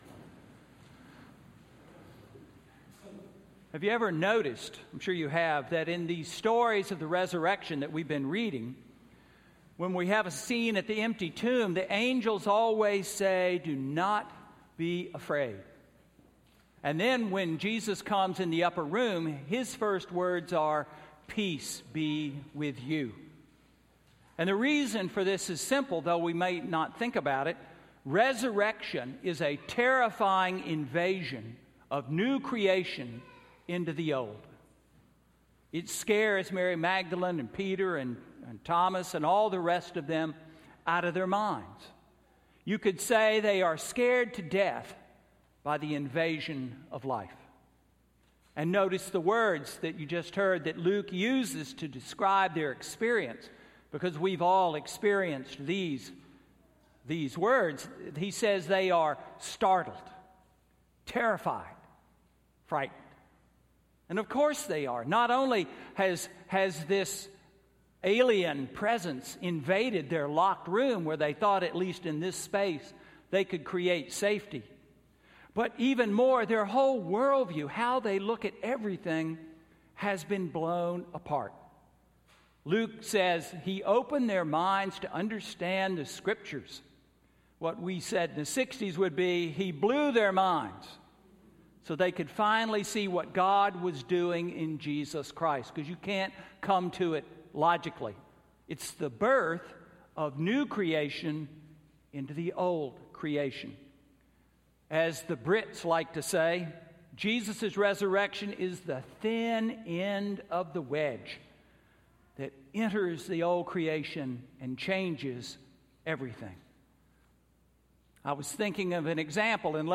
Sermon–Easter 3–April 19, 2015